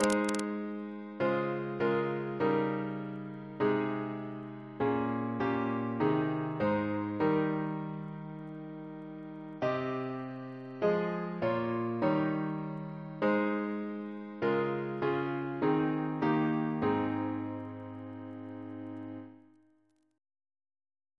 Double chant in A♭ Composer: Henry J. Gauntlett (1805-1876) Reference psalters: H1982: S442; RSCM: 56